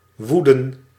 Ääntäminen
IPA: /ˈʀaːzn̩/